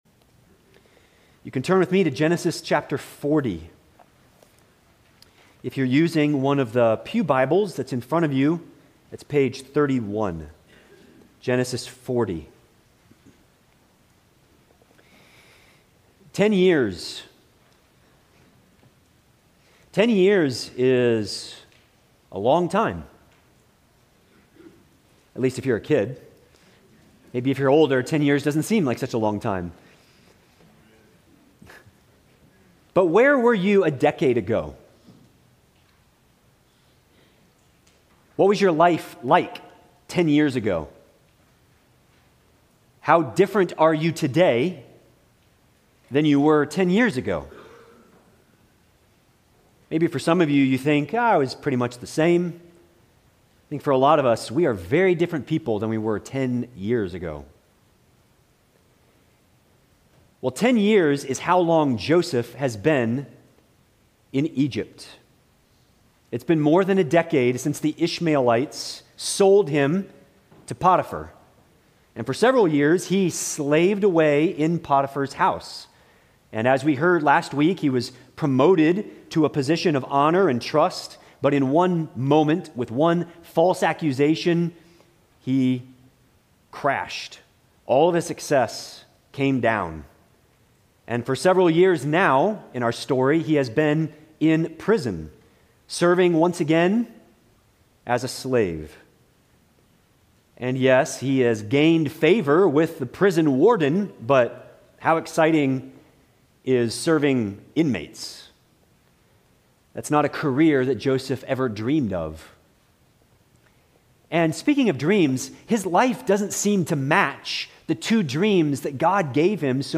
Sermons – Bethany Baptist Church Brevard, NC